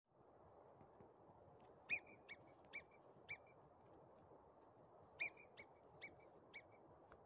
Marmots at the Grossglockner
Marmots are especially famous for their warning calls or whistles.
As soon as the marmots noticed dogs, they let out their characteristic whistles and fled.